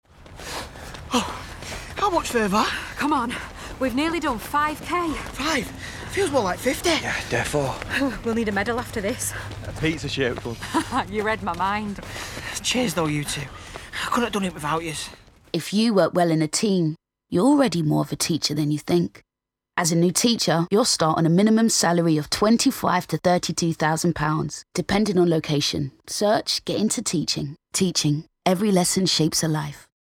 Department of Education Radio Ad